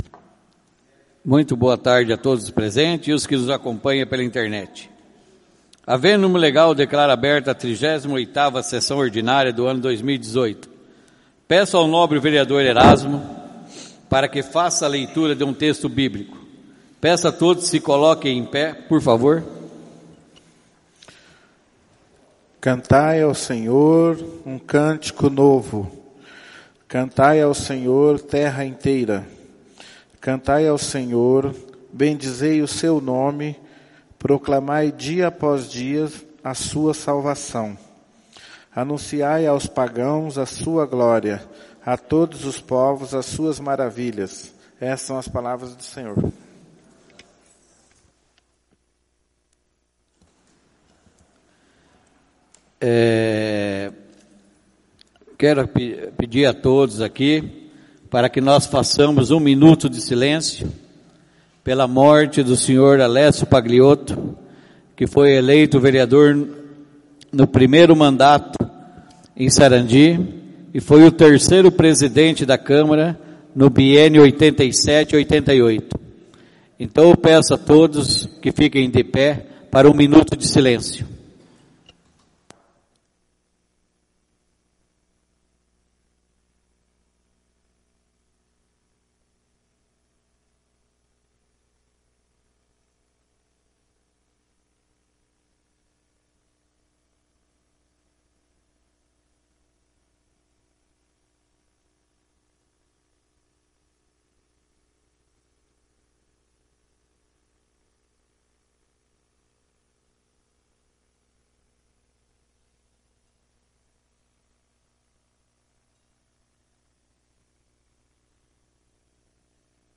O Presidente da Câmara Municipal de Sarandi-PR. Sr. Carlos Roberto Falaschi, verificando a existência de quórum legal dá início à 38ª Reunião Ordinária do dia 12/11/2018.
A convite do Senhor Presidente, o edil ERASMO CARDOSO PEREIRA procedeu à leitura de um texto bíblico.